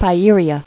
[Pi·e·ri·a]